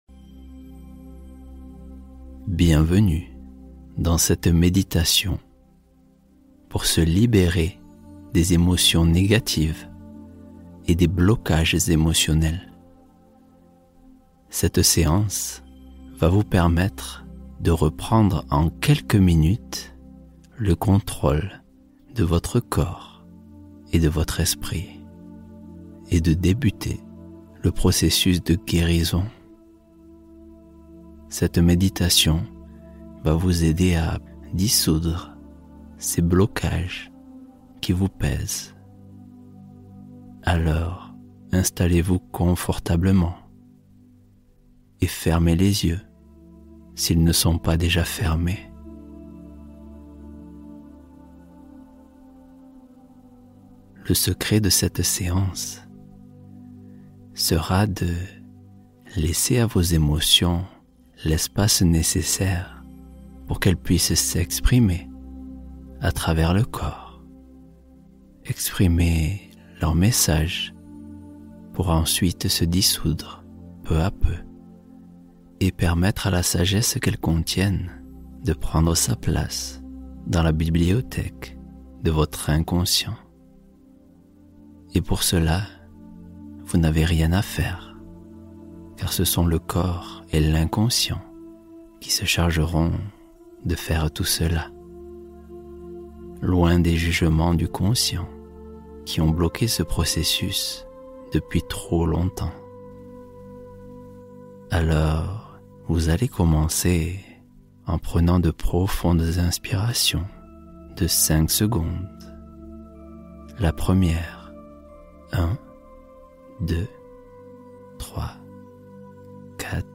Soin Émotionnel : 18 minutes pour apaiser la peur, la honte ou la colère